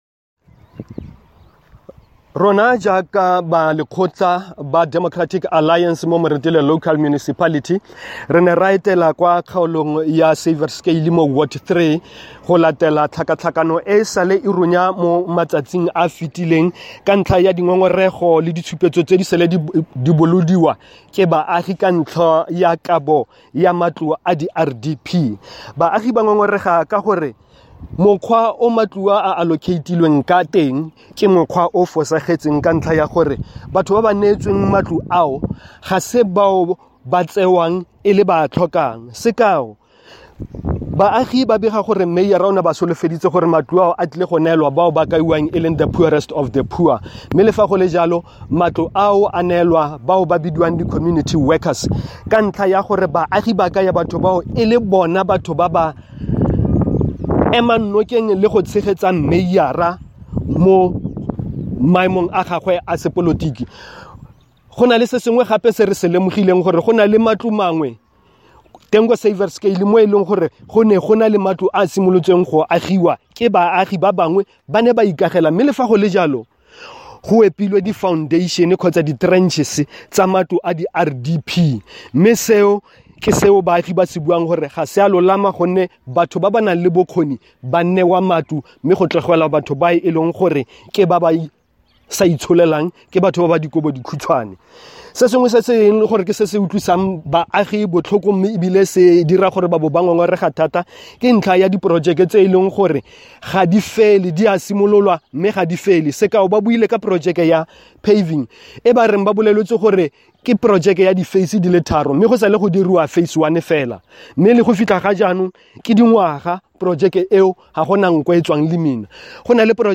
Note to Editors: Please find the attached soundbite in
Setswana from the DA Caucus Leader, Councillor Solly Magalefa.